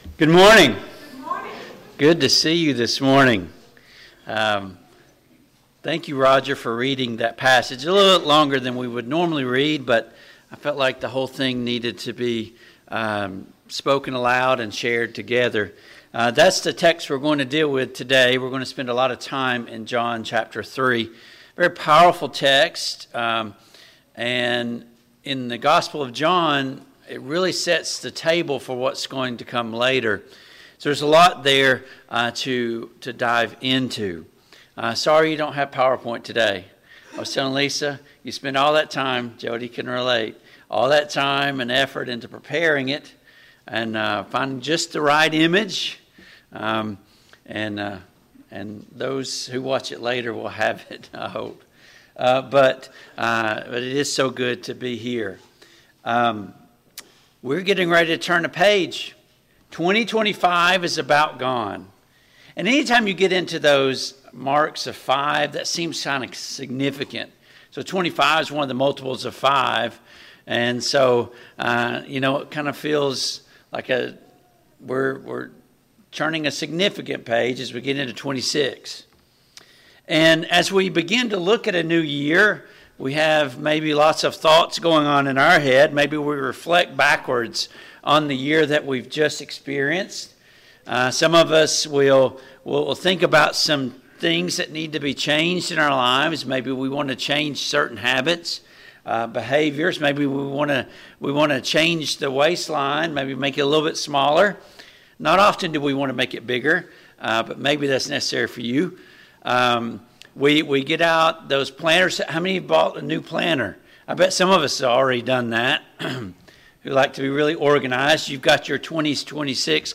John 3:1-21 Service Type: AM Worship Download Files Notes Topics: Resolutions , Transformation « 14.